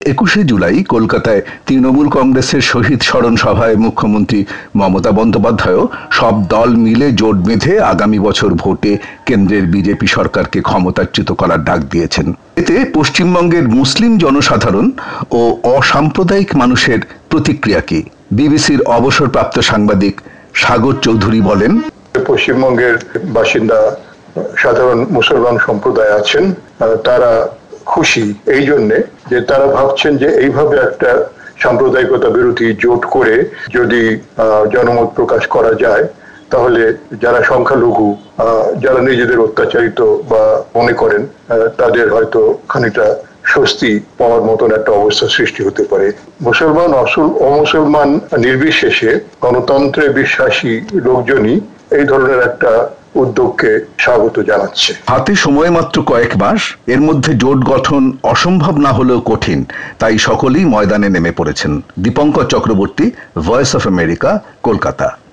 প্রতিবেদন।